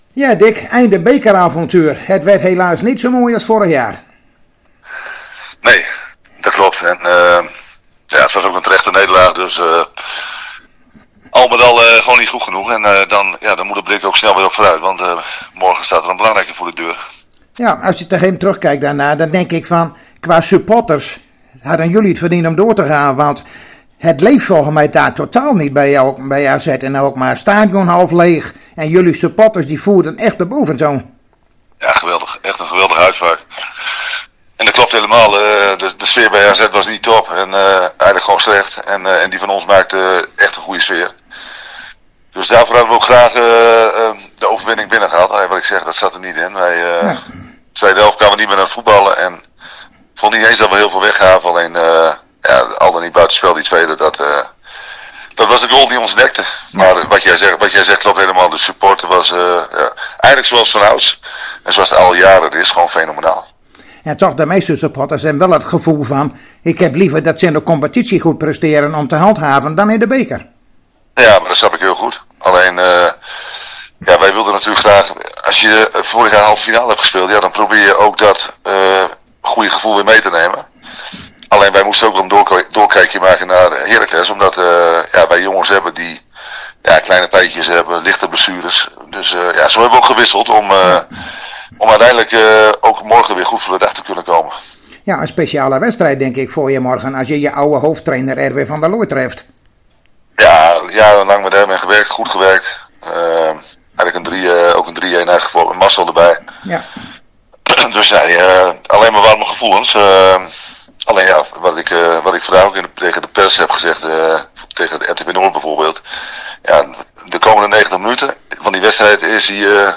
Zojuist spraken wij weer telefonisch met trainer Dick Lukkien van FC Groningen over de wedstrijd van morgenavond uit tegen Heracles Almelo.